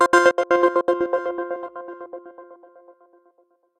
meteor.ogg